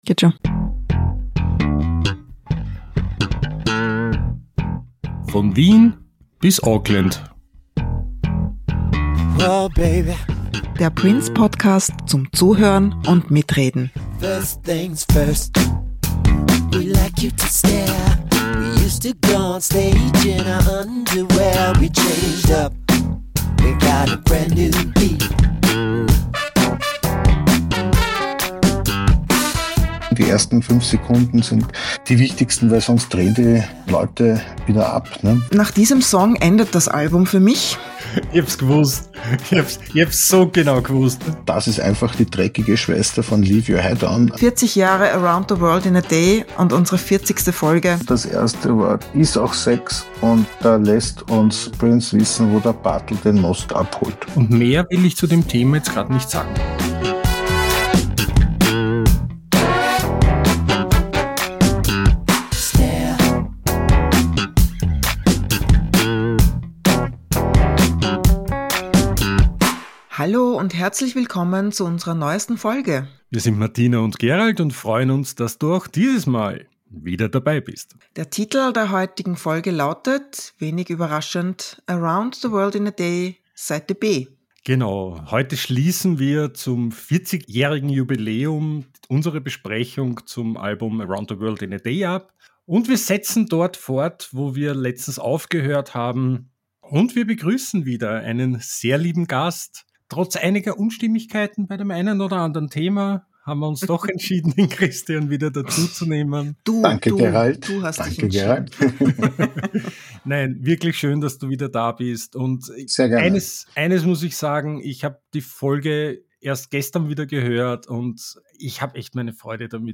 Außerdem gibts eine Interpretation des Albumscovers, einen kleinen Ausflug in die 80er Jahre mit einem Blick auf die Minnesota Music Awards und vieles mehr. Dazu natürlich eine entspannte Plauderei - wie immer persönlich, nerdy und mit einer Prise Augenzwinkern – über Lieblingszeilen, versteckte Botschaften, Maxisingles, alte Erinnerungen … und wie sich unser Blick auf das Album im Lauf der Jahre verändert hat.